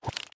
Minecraft Version Minecraft Version 25w18a Latest Release | Latest Snapshot 25w18a / assets / minecraft / sounds / entity / bobber / retrieve2.ogg Compare With Compare With Latest Release | Latest Snapshot
retrieve2.ogg